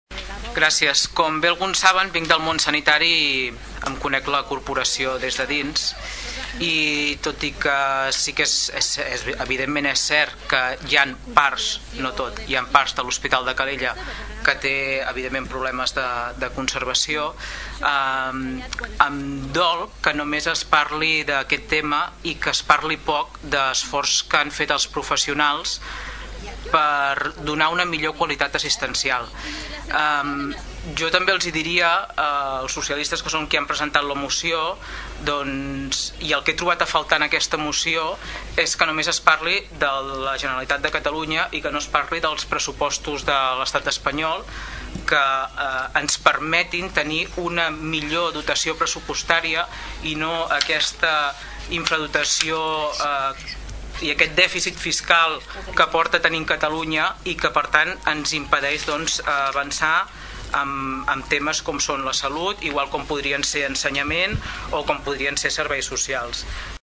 Per altra banda, la Anna Serra, des del grup d’ERC, reclamava que només es remarqui i es parli de l’esforç econòmic i no del professional, per part dels treballadors, assenyalant i criticant el grup del PSC per només parlar de la Generalitat de Catalunya i no dels pressupostos de l’Estat espanyol.